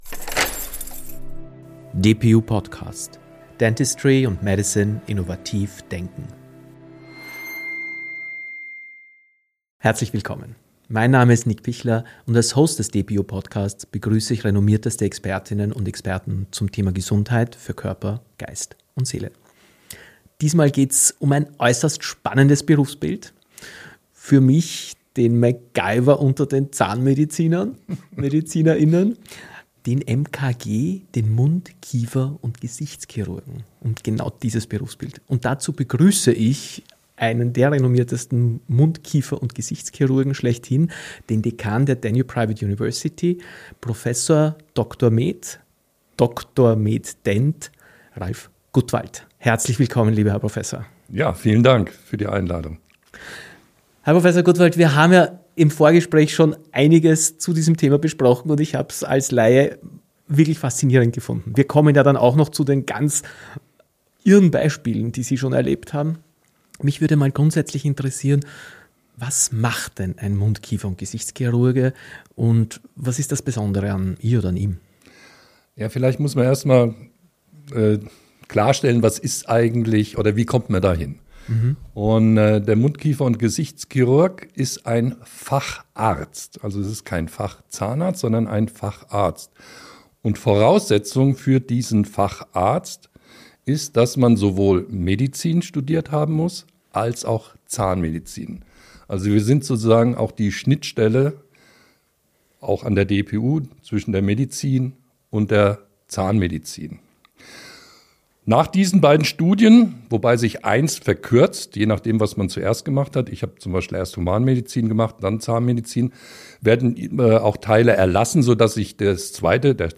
Ein spannendes und tiefgehendes Gespräch über Präzision, Innovation und die emotionale Kraft medizinischer Exzellenz.